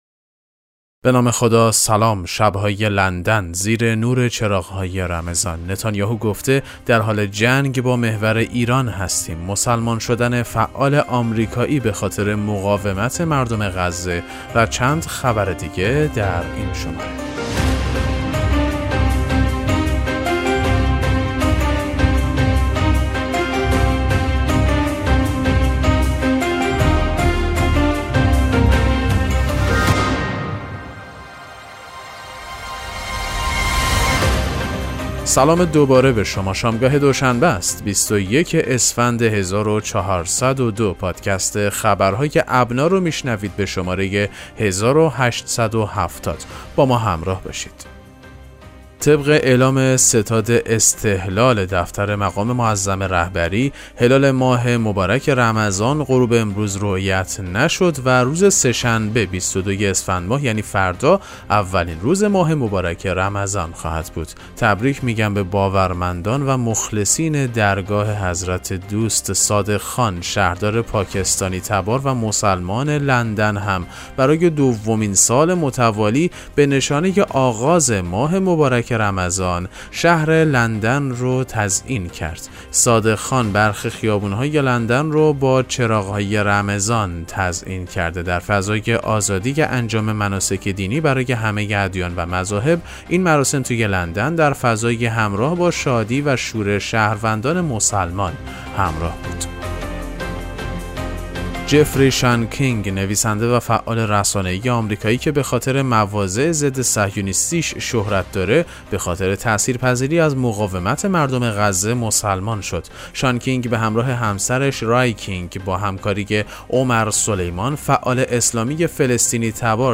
پادکست مهم‌ترین اخبار ابنا فارسی ــ 21 اسفند 1402